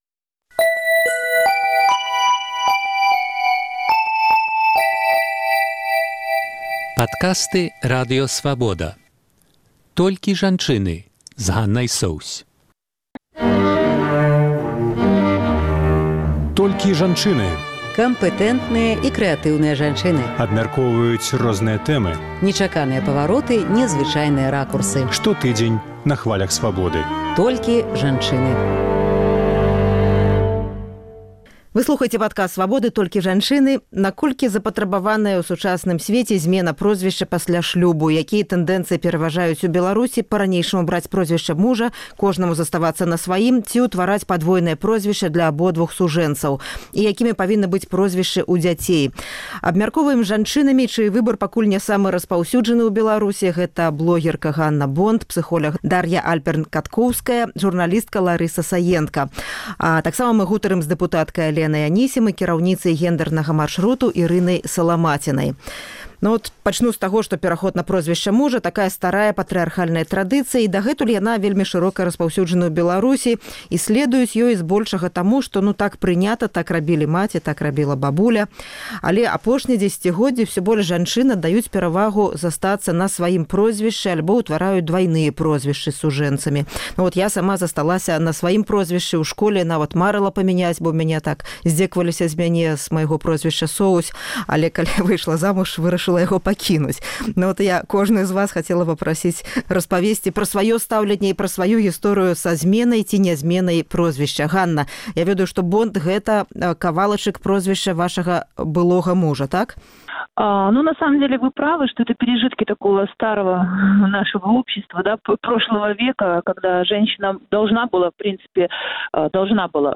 Наколькі запатрабаваная ў сучасным сьвеце зьмена прозьвішча ў шлюбе? Якія тэндэнцыі пераважаюць у Беларусі — браць прозьвішча мужа, кожнаму заставацца на сваім ці ўтвараць падвойнае прозьвішча для абодвух сужэнцаў. Якімі маюць быць прозьвішчы дзяцей? Абмяркоўваем з жанчынамі, чый выбар пакуль ня самы распаўсюджаны ў Беларусі